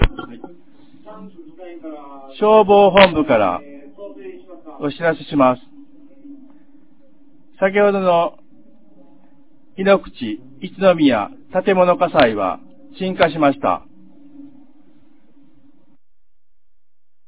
2025年01月01日 08時36分に、安芸市よりへ放送がありました。
放送音声